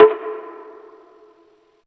176TTPERC1-R.wav